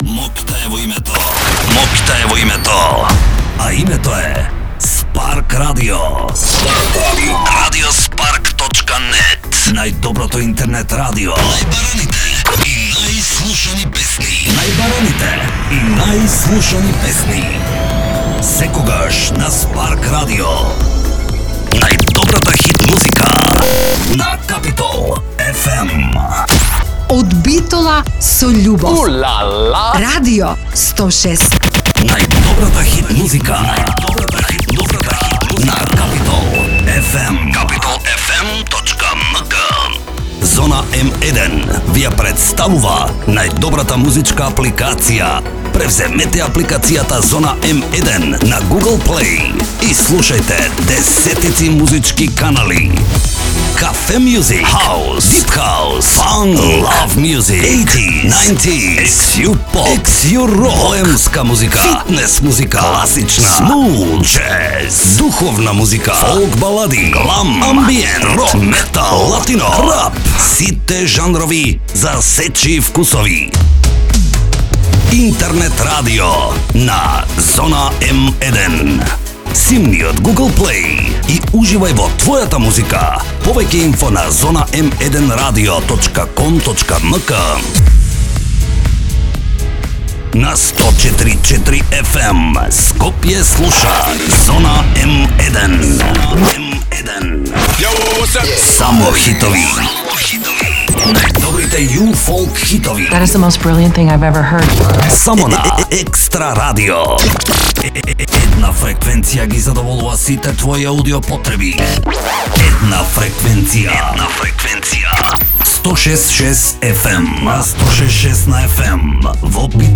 Џингл пакети за радио станици (свипери, џинглови за идентификација на радија, почетни џинглови за емисии, радио најави, џинглови за спецификација на програми и слично).
Демо матерјалите се со голема mp3 компресија, а оригиналната продукција е со многу повисок квалитет!
Демо 9 (Radio Jingles and Sweepers 2025)
Demo 9 (Radio Jingles and Sweepers 2025).mp3